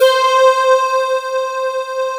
Index of /90_sSampleCDs/Big Fish Audio - Synth City/CD1/Partition B/05-SYNTHLEAD